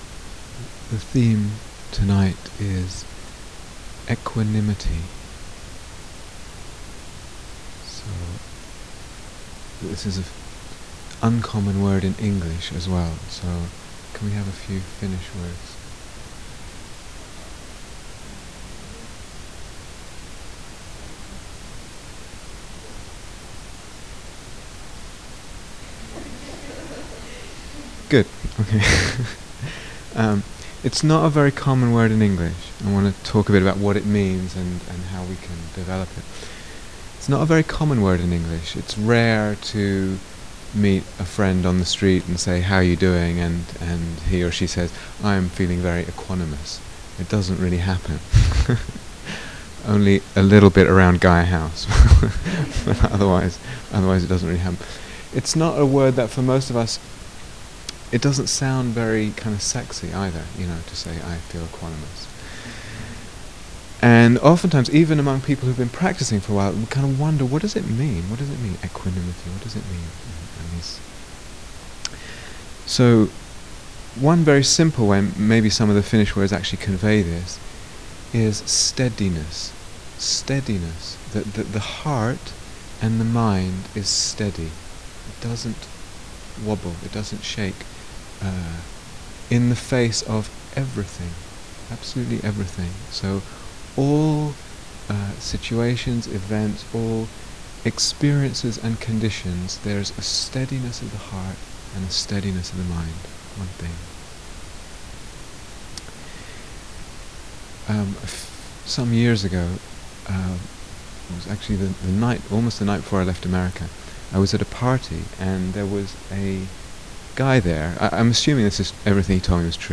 Equanimity Download 0:00:00 --:-- Date 15th October 2007 Retreat/Series Silent Autumn Retreat, Finland 2007 Transcription The theme tonight is equanimity.